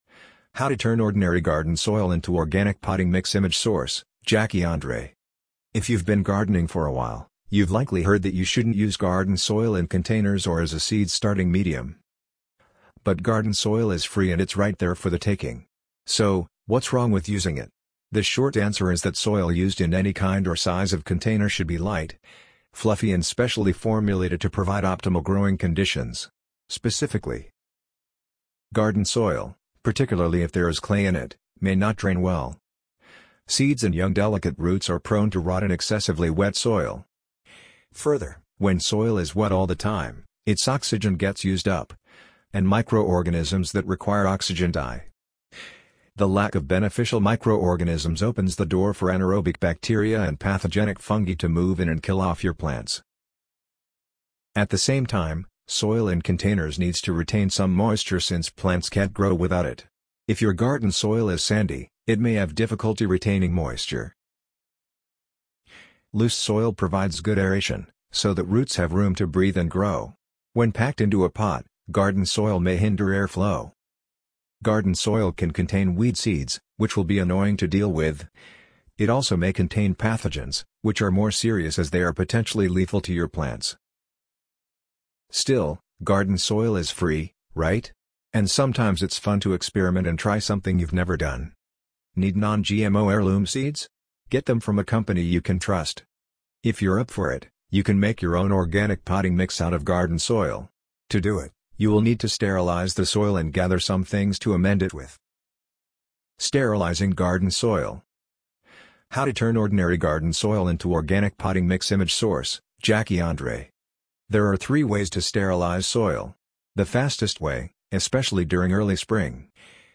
amazon_polly_75008.mp3